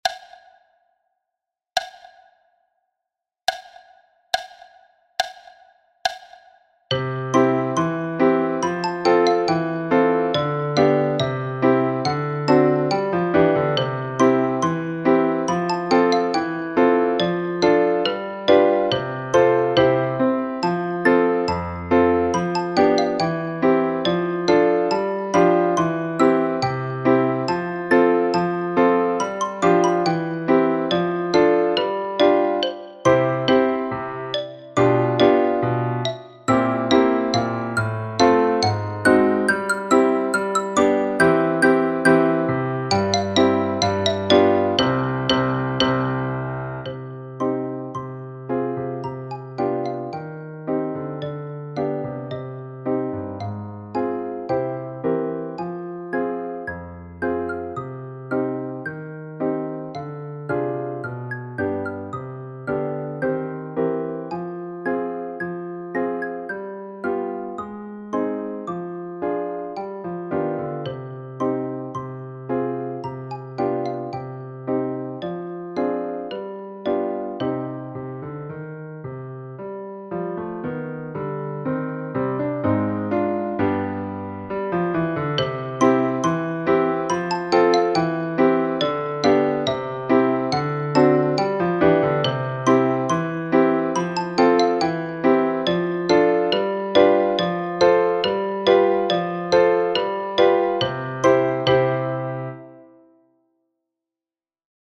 Après la pluie – vo – 70 bpm